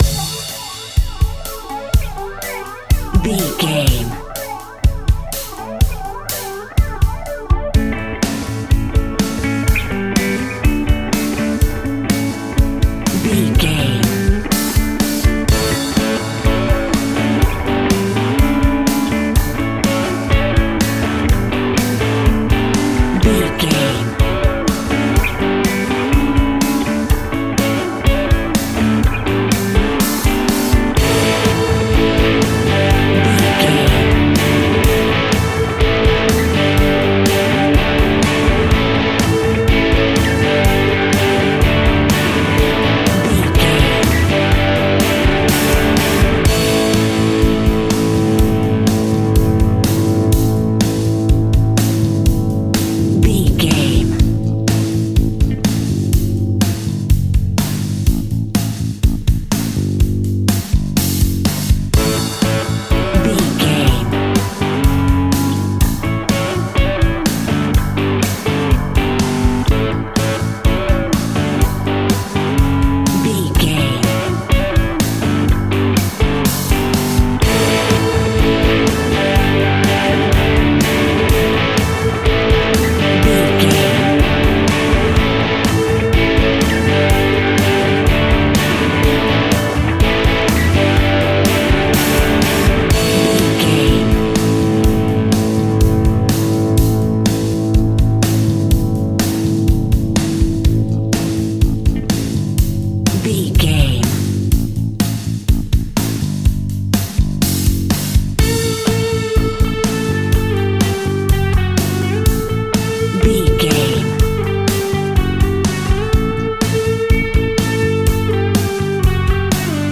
Uplifting
Mixolydian
80s
rock
hard rock
heavy metal
blues rock
distortion
instrumentals
rock guitars
Rock Bass
heavy drums
distorted guitars
hammond organ